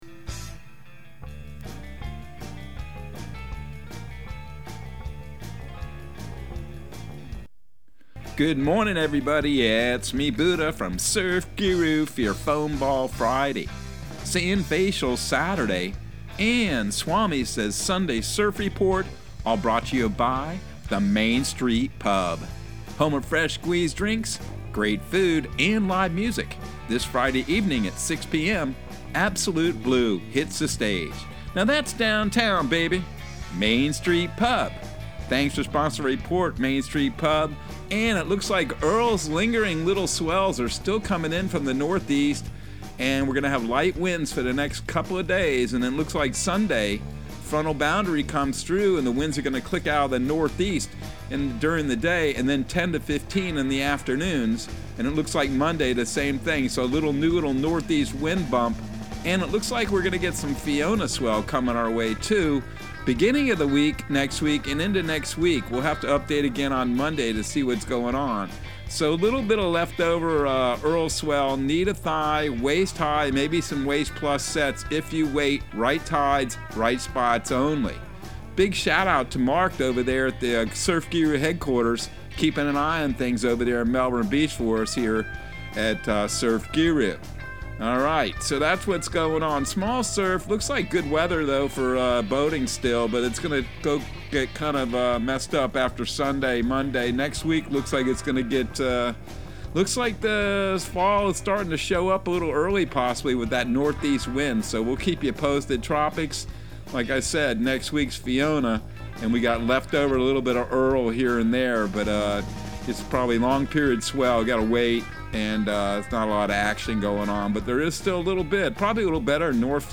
Surf Guru Surf Report and Forecast 09/16/2022 Audio surf report and surf forecast on September 16 for Central Florida and the Southeast.